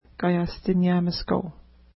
Kaiashtiniamishkau Next name Previous name Image Not Available ID: 371 Longitude: -61.0087 Latitude: 54.2593 Pronunciation: ka:ja:stnja:miska:w Translation: Unknown Official Name: Otter Lake Feature: lake